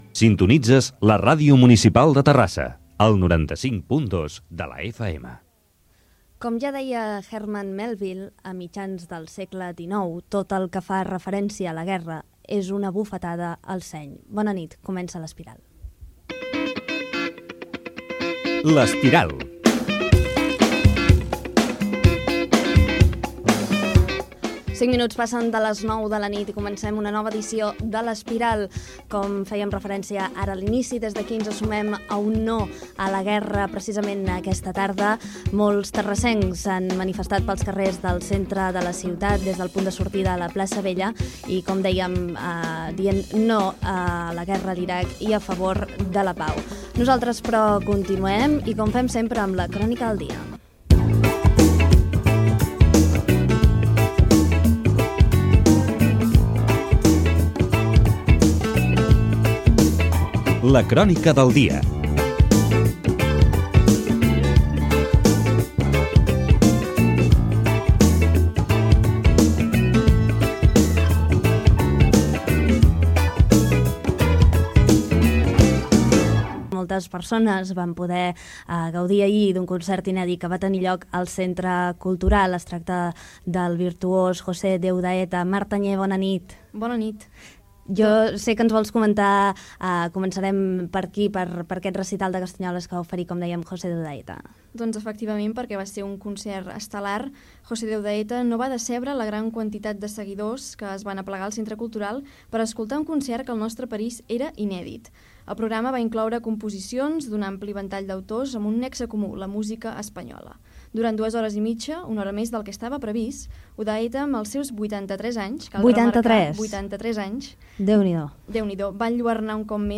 Indicatiu de la ràdio, frase, indicatiu del programa, rebuig a la Guerra d'Iraq, "La crònica del dia" dedicada a un concert de castanyoles de José de Udaeta i el Carnaval
FM